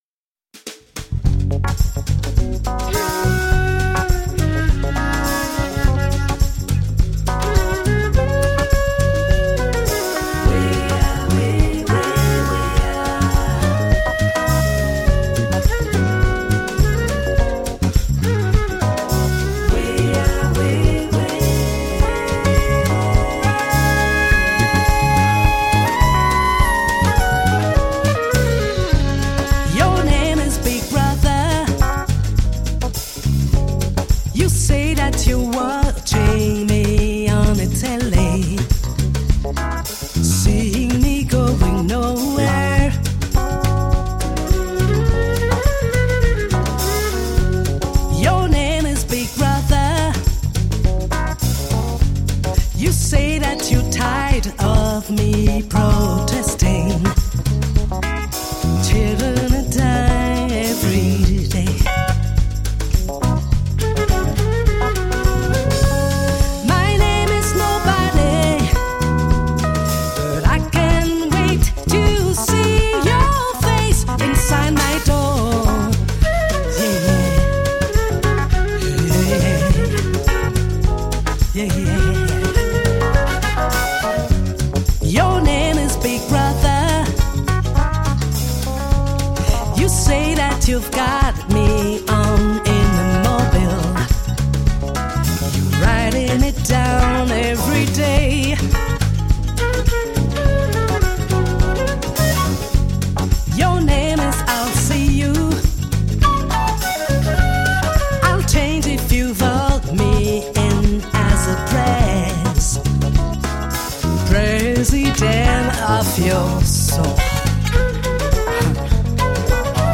Tagged as: World, Other, African influenced